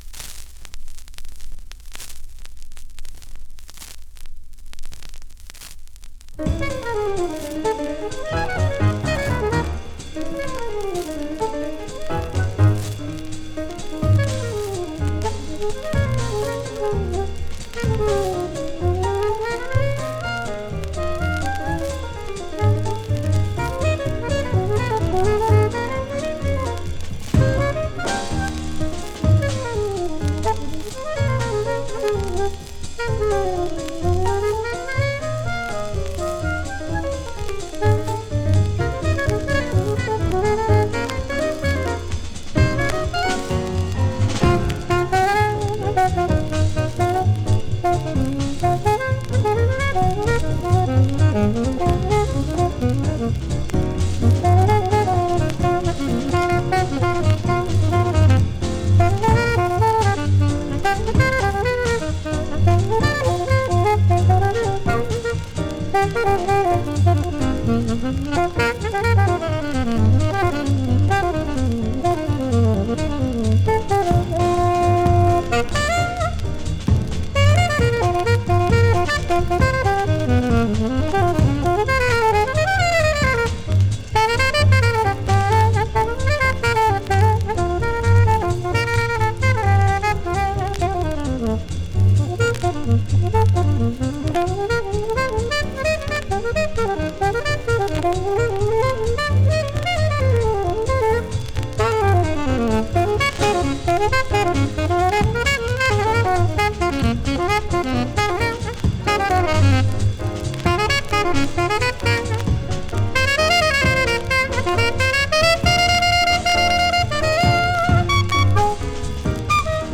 Recorded:  2 March, 1953 at Oberlin College, Oberlin, OH
Alto Sax
Piano
Bass
Drums
Sharp-eared listeners will hear the edit at the 1:13 mark.